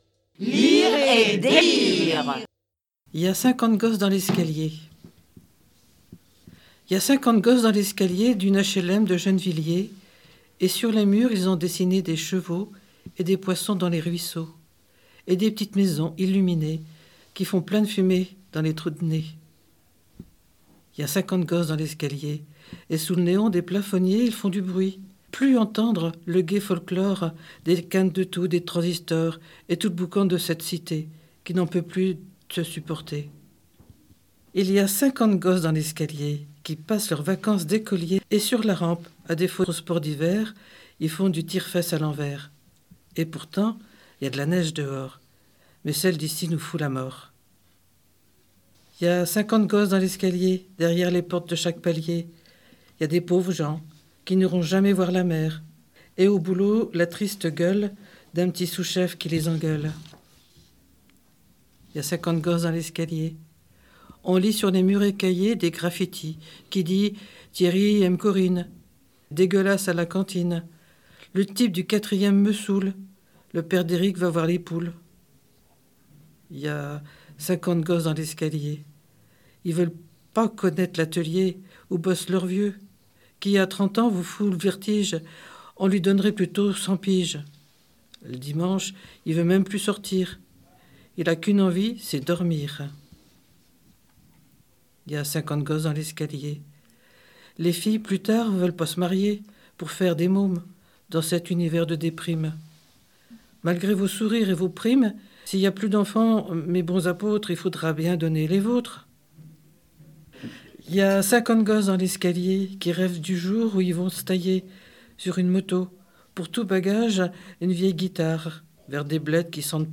Dans cette émission, nous écoutons des extraits des livres suivants : « Il y a cinquante gosses dans l’escalier » de Pierre Perret et « La frousse » d’Isabelle Wlodarczyk.